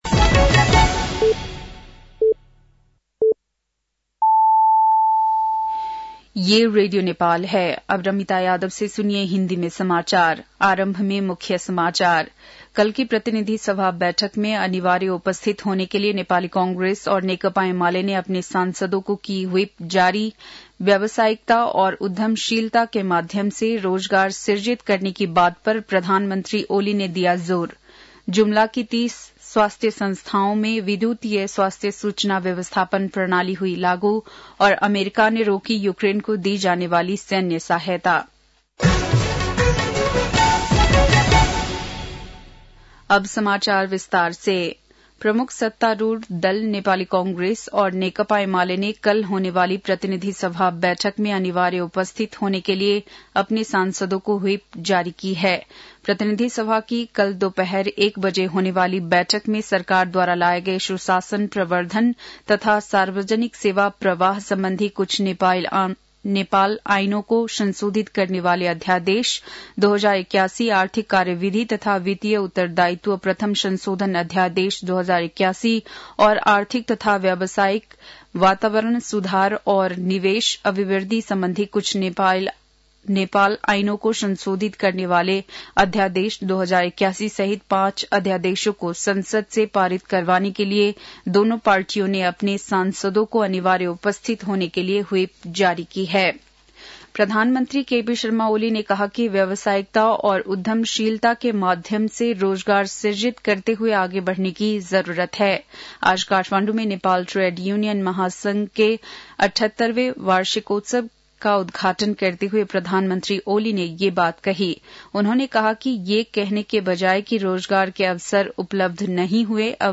बेलुकी १० बजेको हिन्दी समाचार : २१ फागुन , २०८१